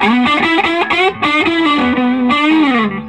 Index of /90_sSampleCDs/USB Soundscan vol.22 - Vintage Blues Guitar [AKAI] 1CD/Partition C/13-SOLO B060